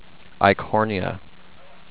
ike-HOR-nee-uh